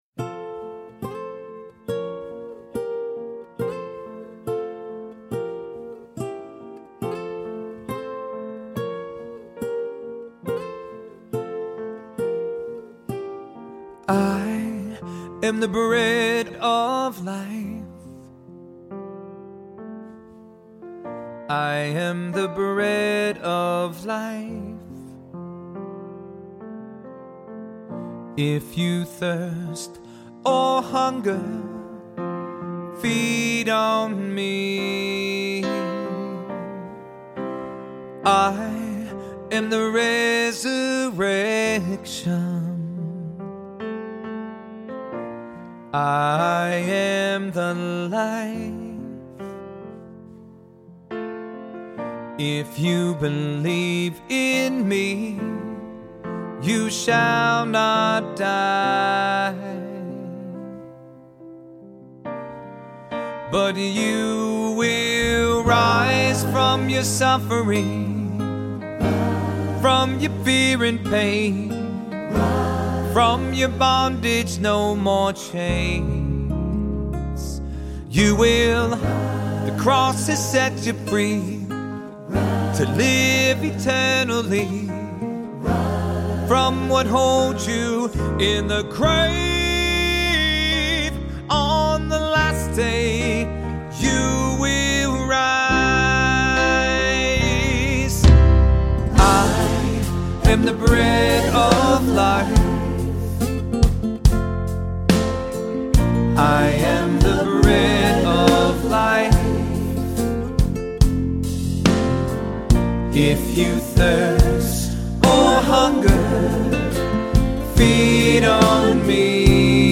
Voicing: Cantor,SATB, assembly